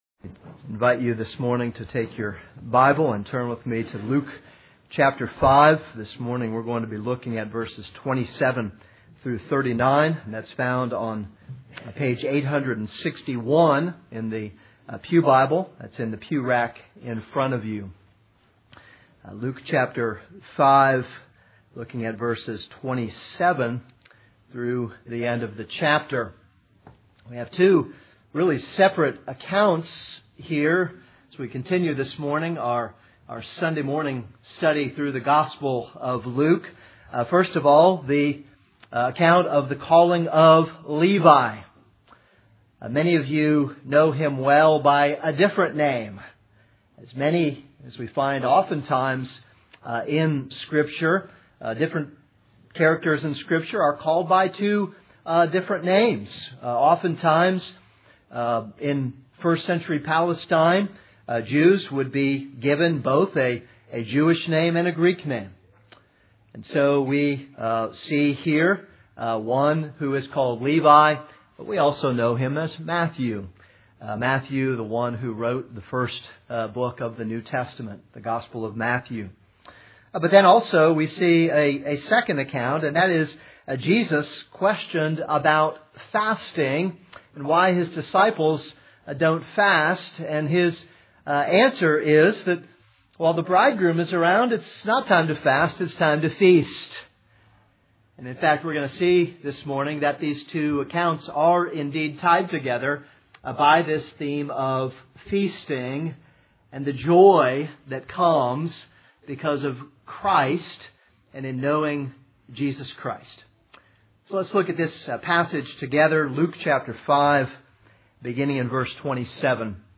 This is a sermon on Luke 5:27-39.